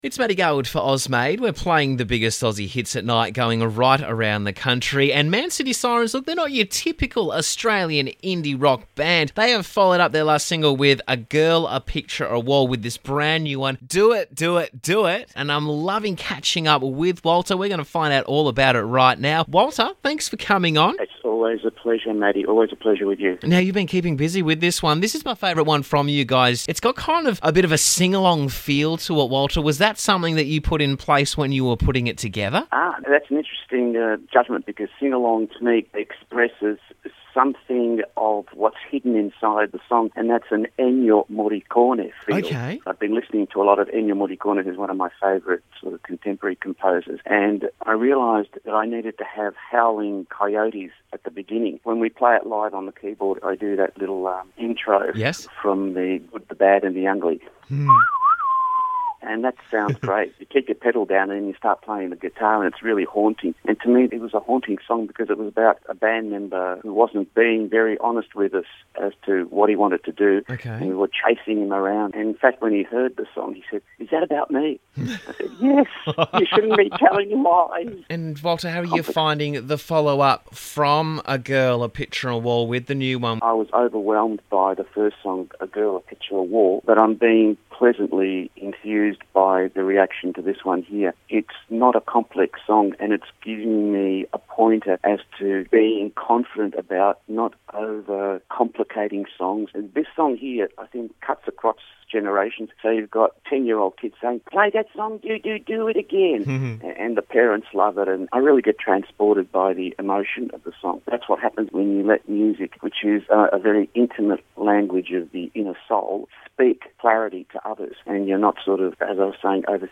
MAN CITY SIRENS is not your typical Australian indie rock band.
vocals, guitar, keys
bass
drums, percussion, harmonica), combine a unique blend of British rock meets 17th and 18th century Italian arias meets 60s lyrical pop.